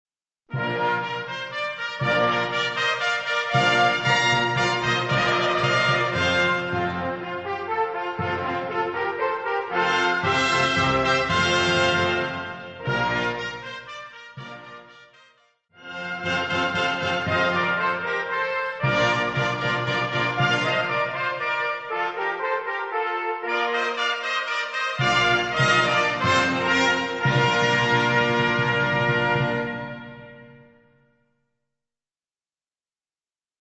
Gattung: Intraden
Besetzung: Blasorchester